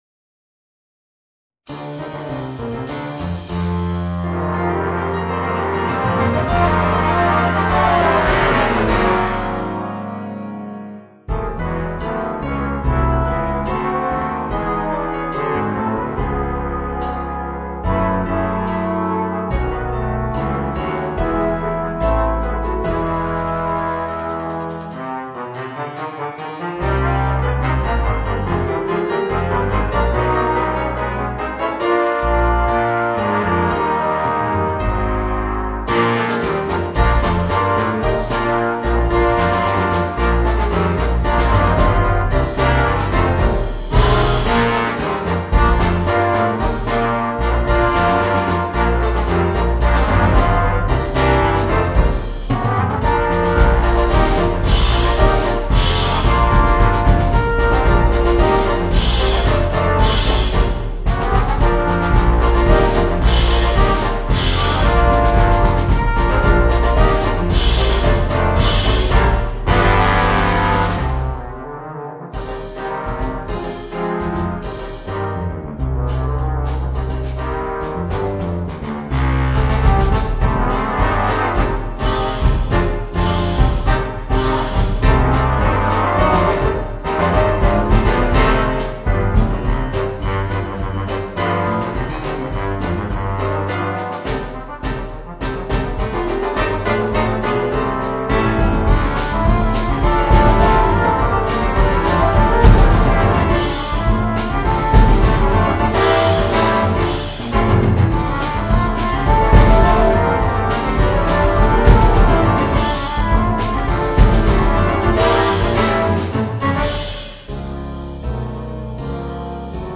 Voicing: 4 Trombones and Rhythm Section